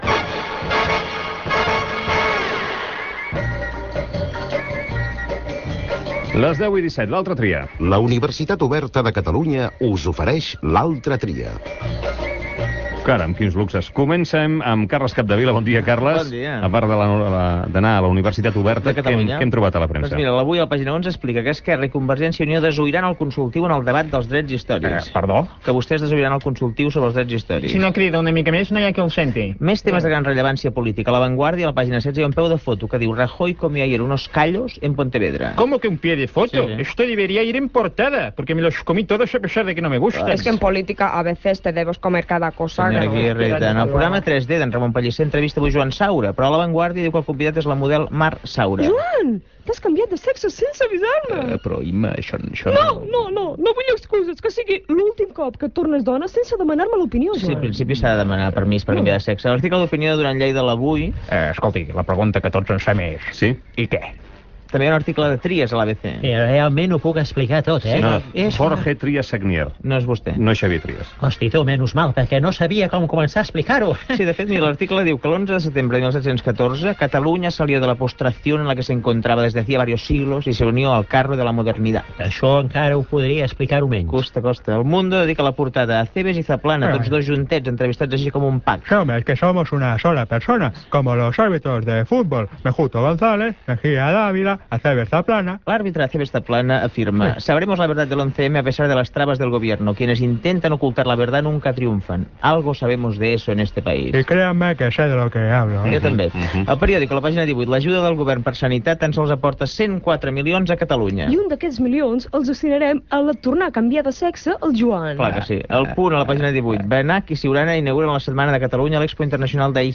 Espai "L'altra tria", repàs humorístic a la premsa del dia
Info-entreteniment
FM
Programa presentat per Antoni Bassas.